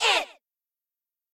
okItsOngirls3.ogg